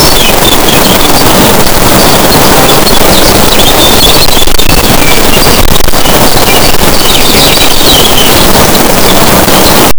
Soft early-morning countryside ambience with distant birdsong, light wind moving through hedgerows, calm and spacious, no human activity.
soft-early-morning-countr-ttvfokjo.wav